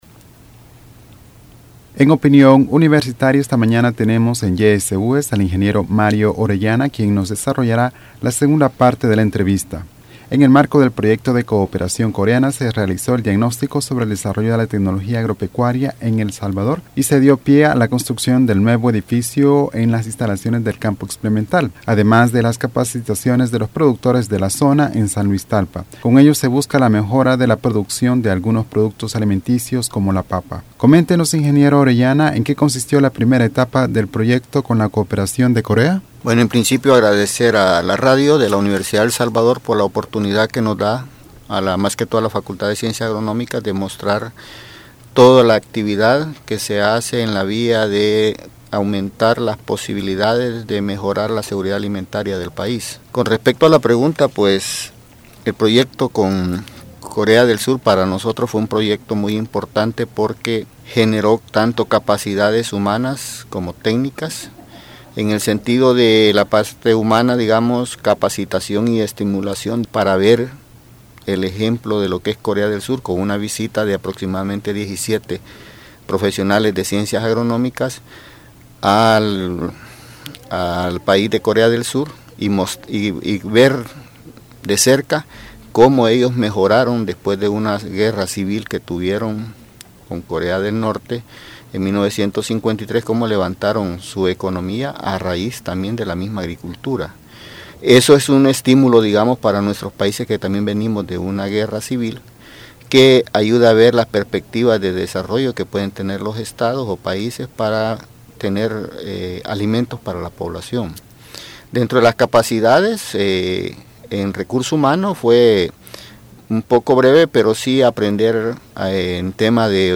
Entrevista Opinion Universitaria (13 Abril 2015):Proyecto de desarrollo de Tecnología agropecuaria en El Salvador.